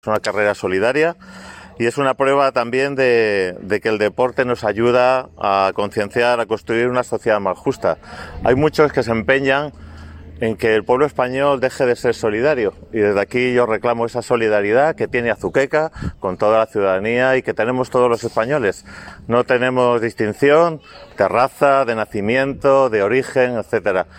Declaraciones de Ángel Fernández (delegado provincial de Educación, Cultura y Deportes )